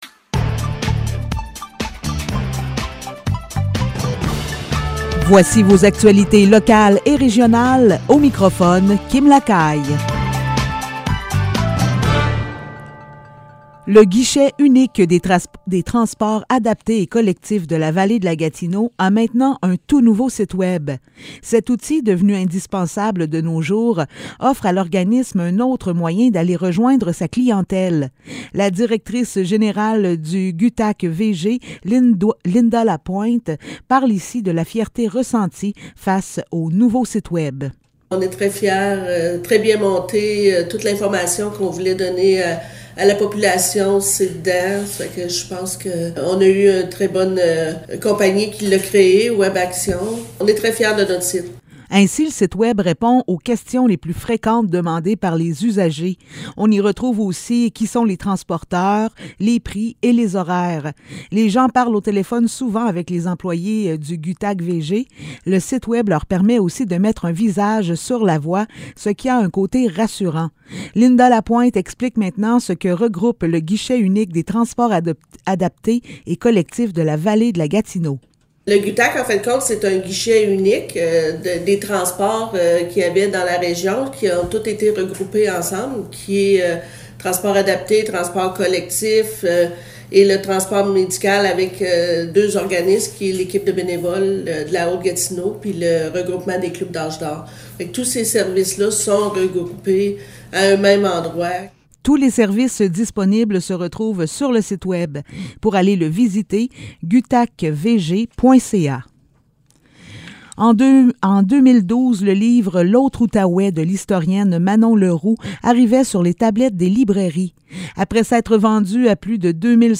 Nouvelles locales - 16 février 2022 - 15 h